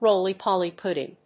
アクセントróly‐pòly púdding